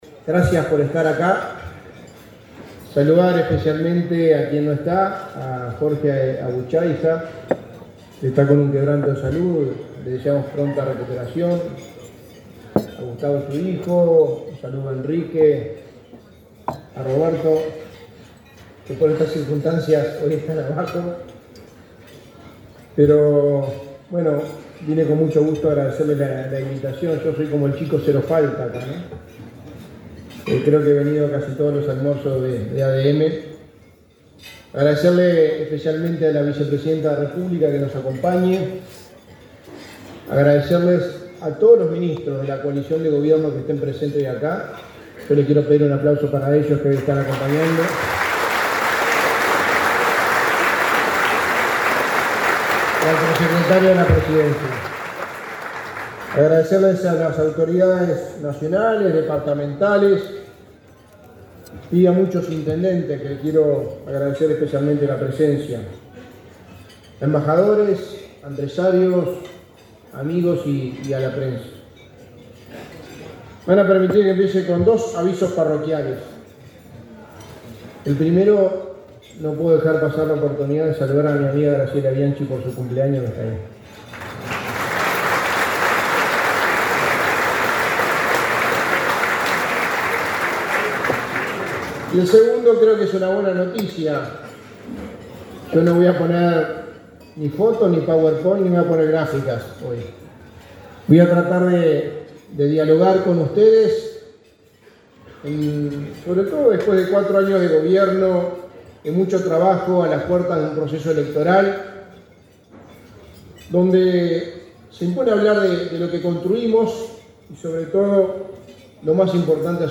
Disertación del secretario de Presidencia, Álvaro Delgado
Disertación del secretario de Presidencia, Álvaro Delgado 14/11/2023 Compartir Facebook X Copiar enlace WhatsApp LinkedIn El secretario de Presidencia de la República, Álvaro Delgado, disertó, este martes 14 en Montevideo, en un almuerzo de trabajo de la Asociación de Dirigentes de Marketing (ADM).